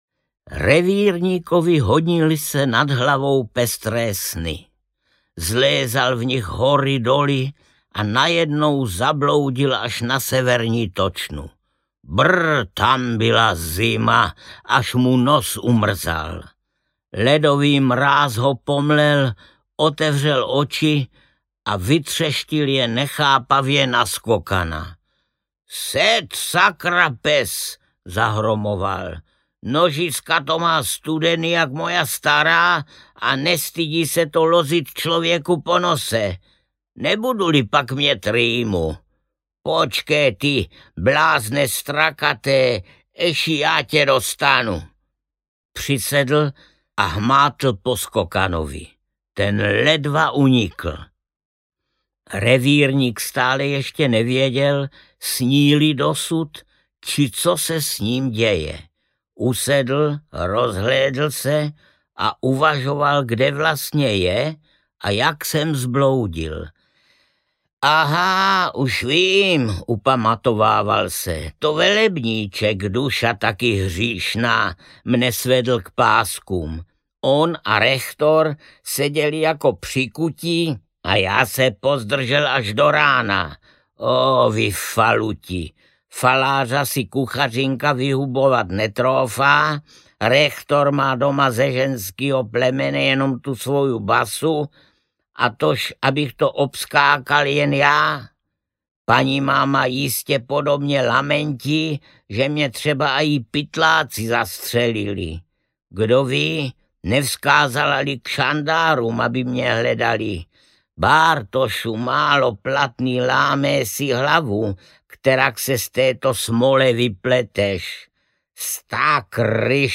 Liška Bystrouška audiokniha
Ukázka z knihy
• InterpretArnošt Goldflam
liska-bystrouska-audiokniha